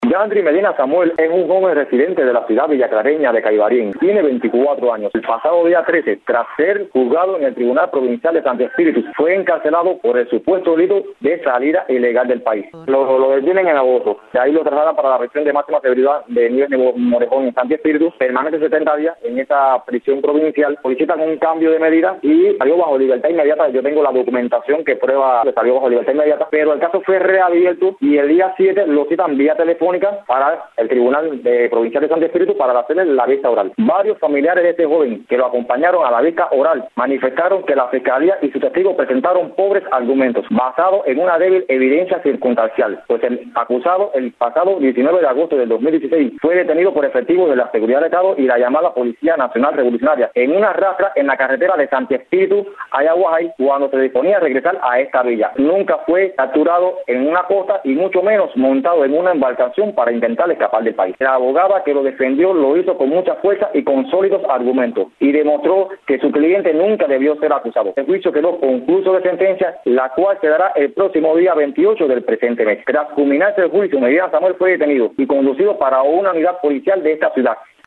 periodista independiente